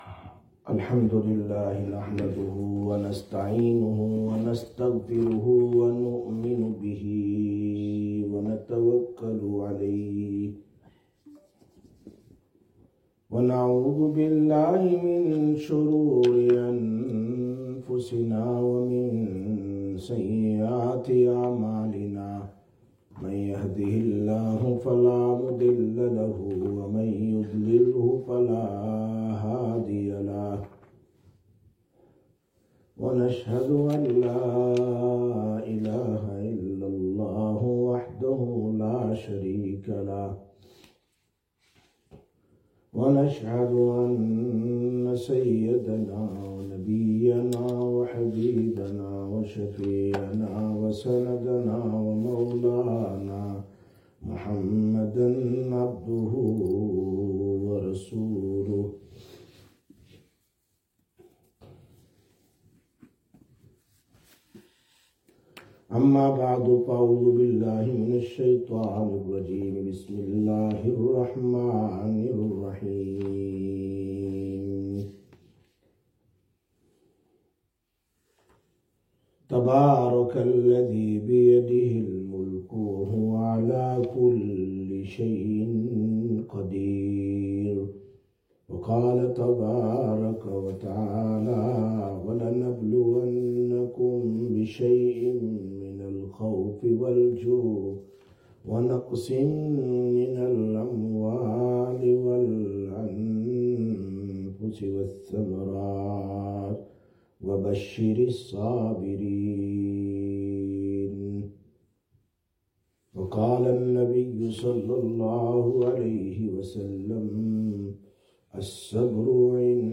27/11/2024 Sisters Bayan, Masjid Quba